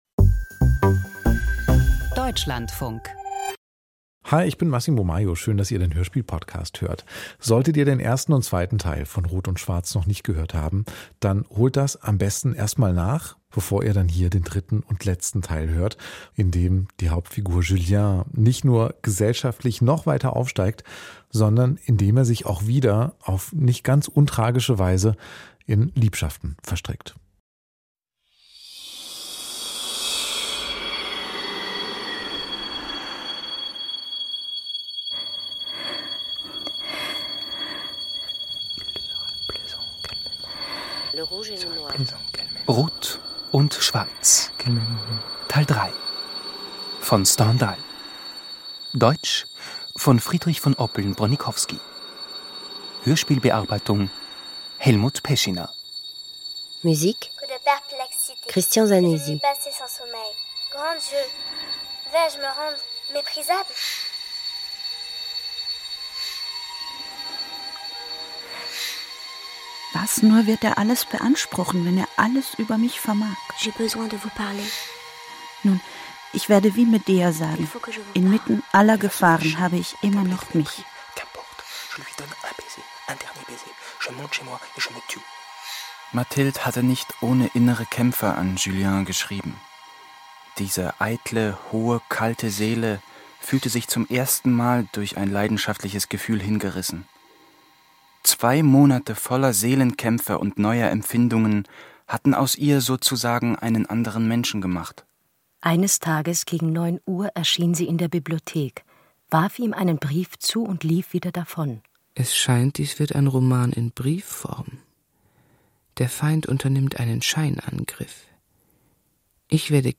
Hörspiel nach Stendhal - Rot und Schwarz (3/3)